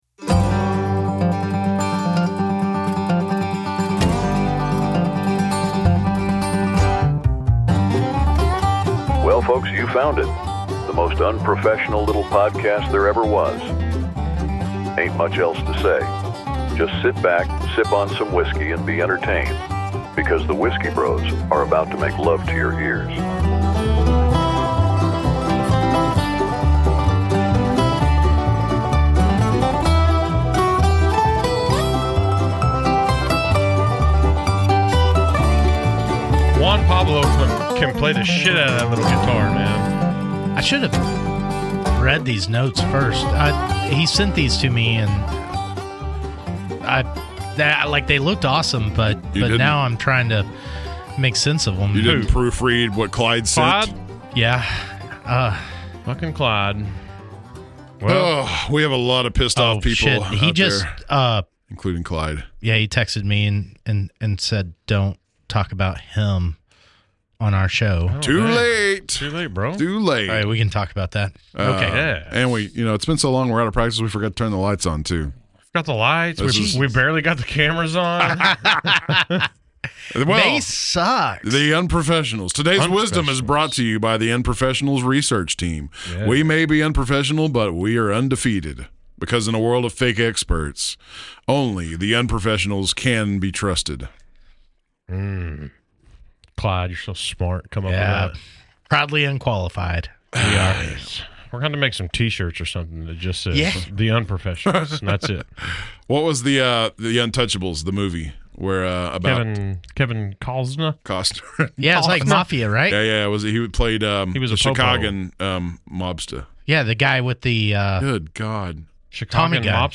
With a steady pour and a healthy disrespect for phonetics, we tackle food lists that were clearly made to humble us.🍣 Best Sushi Around DFW? We butcher the names and still get hungry.